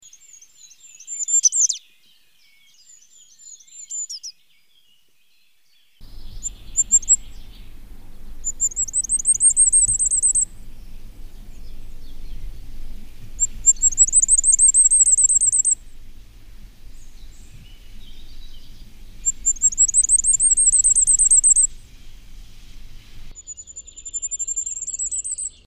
Roitelet à triple bandeau
Regulus ignicapilla
Chant
Roitelet_triple_bandeau.mp3